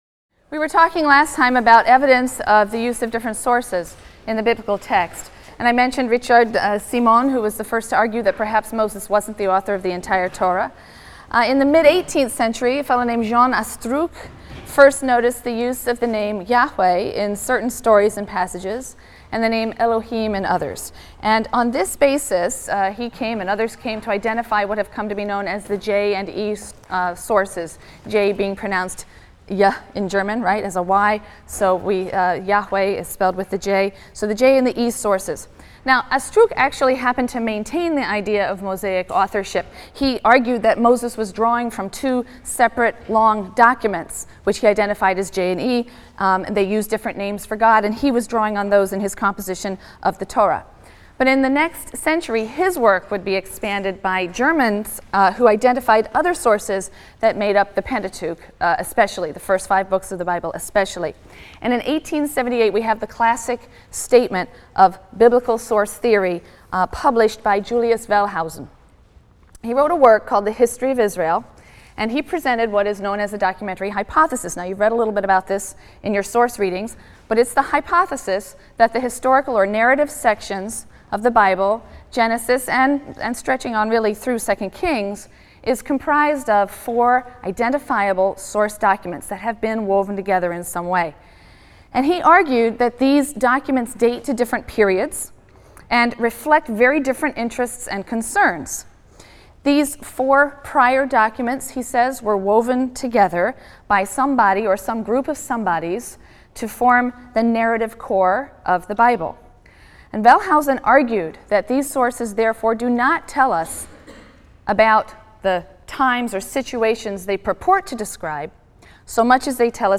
RLST 145 - Lecture 5 - Critical Approaches to the Bible: Introduction to Genesis 12-50 | Open Yale Courses